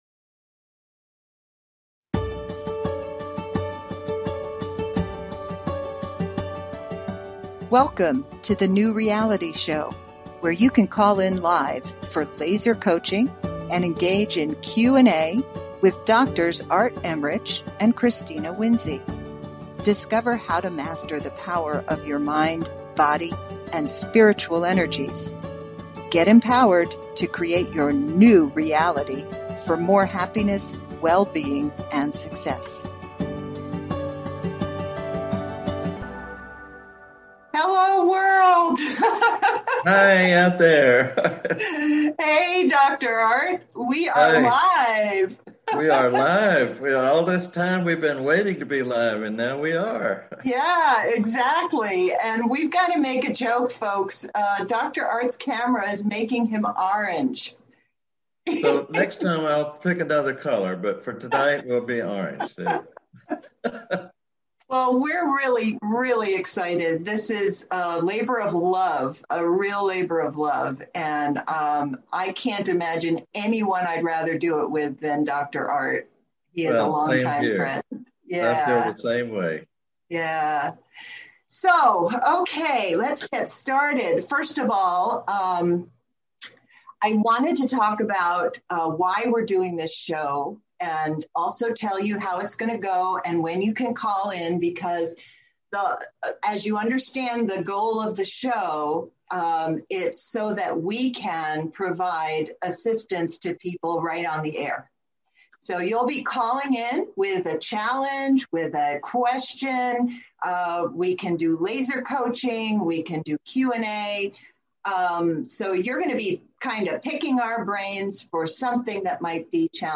Talk Show Episode
These two dynamic co-hosts will help you learn how to take charge of your own amazing mind and emotions, so that you will better navigate these chaotic and stressful times with more ease than you thought possible.